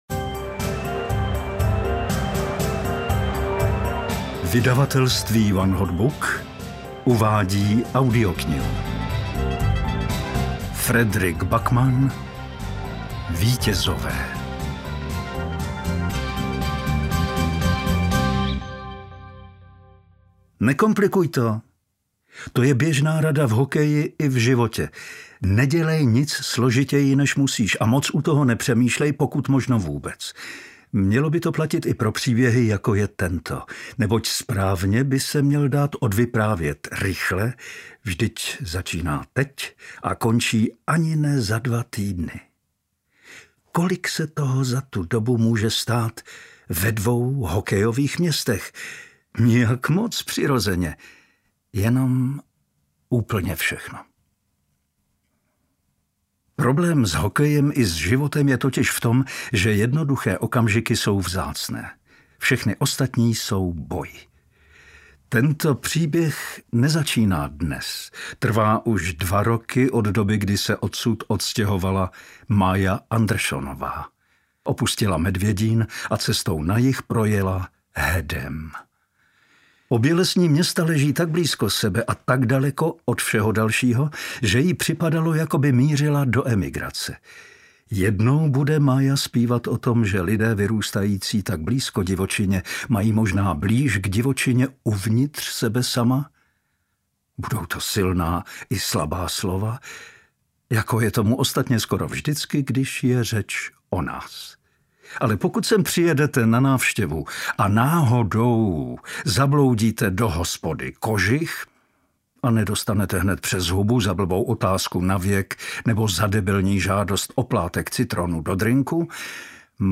Vítězové audiokniha
Ukázka z knihy
• InterpretPavel Soukup